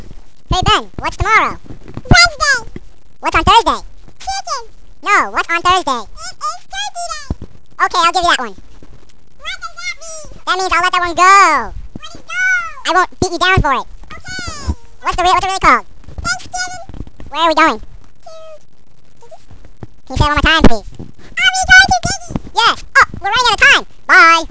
It is suspected that this chipmunk is indeed the chipmunk in the audio clips, probably the one with the lower voice.